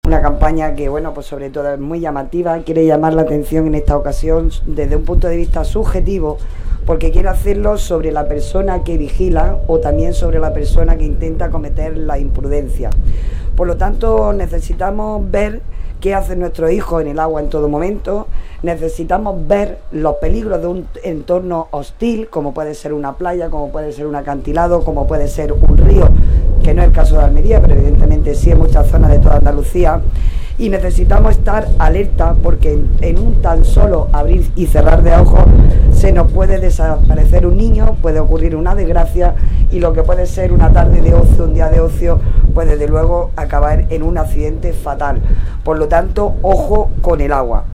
La concejala de Obras Públicas, Mantenimiento, Accesibilidad y Economía Azul del Ayuntamiento de Almería, Sacra Sánchez, y la delegada del Gobierno de la Junta de Andalucía en Almería, Aránzazu Martín, han presentado en la playa de El Palmeral, en el Zapillo, la nueva campaña de la Agencia de Emergencia de Andalucía (EMA) para prevenir ahogamientos y accidentes en el medio acuático este verano, que lleva por lema ‘Ojo con el agua’ y que se emitirá en televisión, radio, prensa y redes sociales.